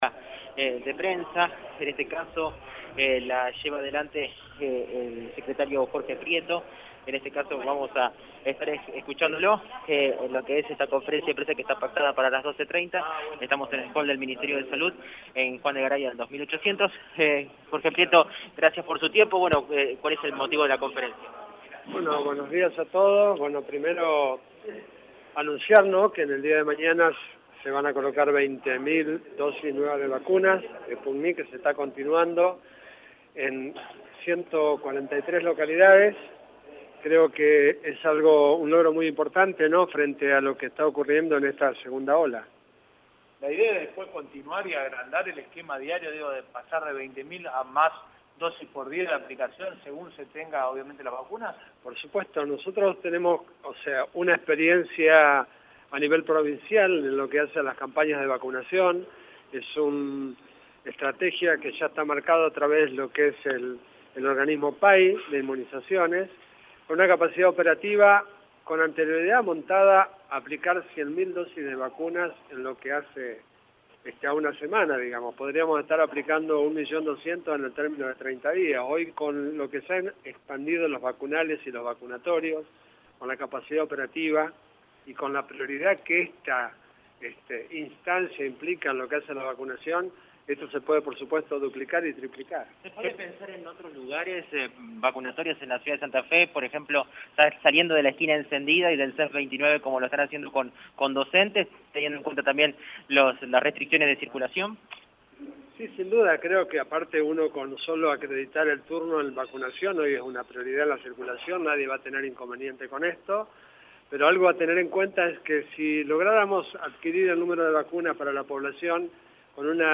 Así lo adelantó el secretario de Salud de la provincia, Jorge Prietto, en conferencia de prensa.